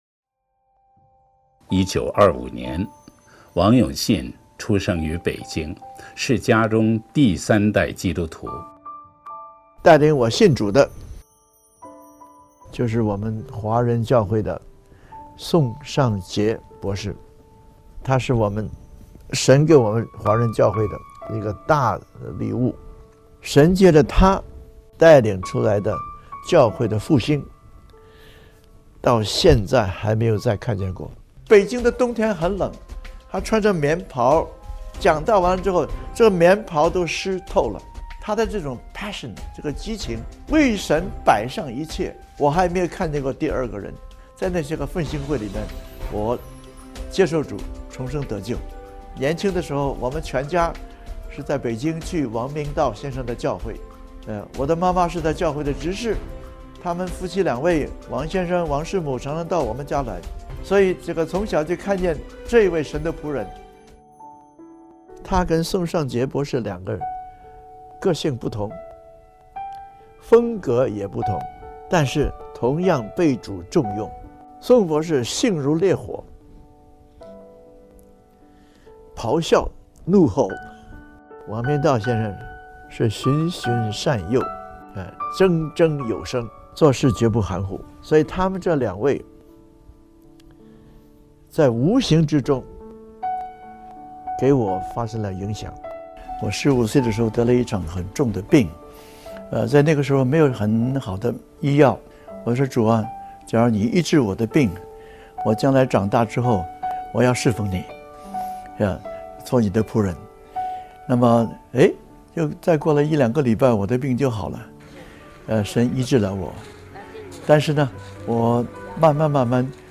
11中国福音大会